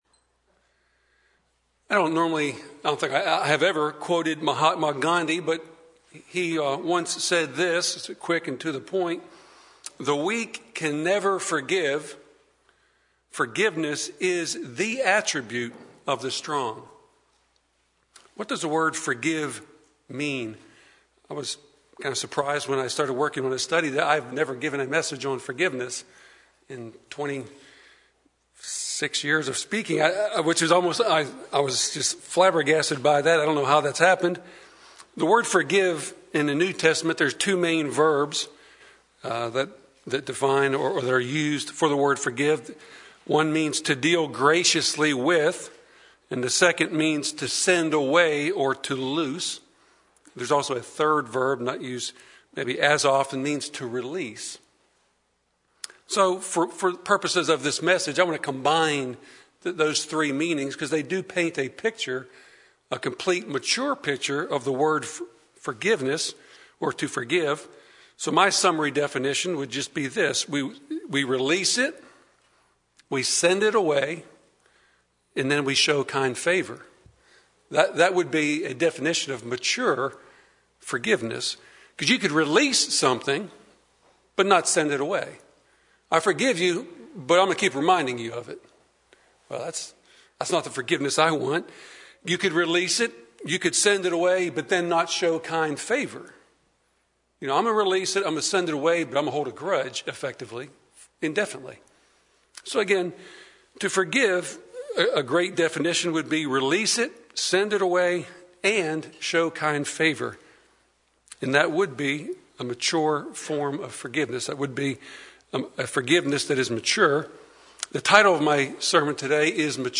Sermons
Given in Nashville, TN